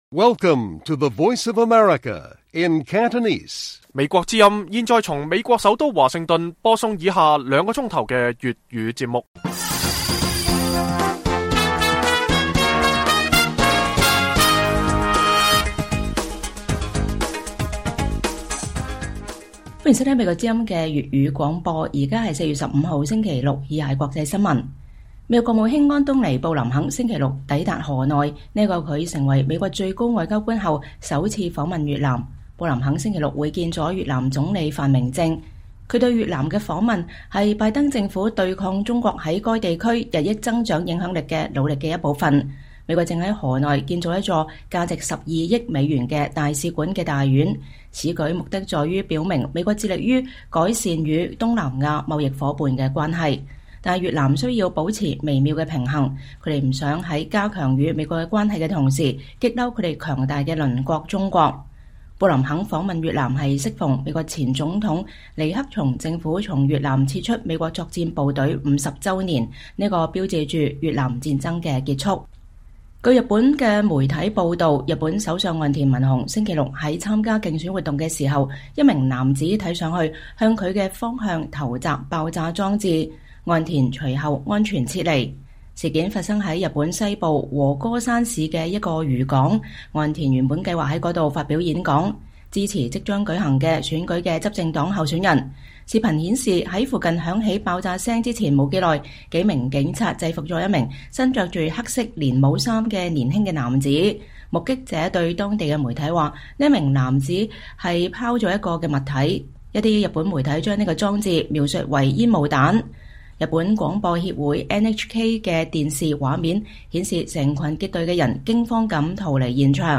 粵語新聞 晚上9-10點：美國國務卿會晤越南總理